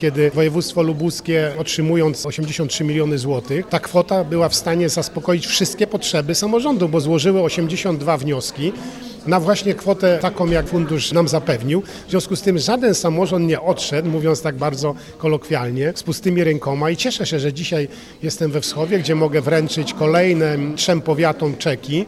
– Jest to sytuacja bez precedensu, bo wszystkie, lubuskie samorządy, które złożyły wnioski, otrzymały dotację – powiedział wojewoda Dajczak: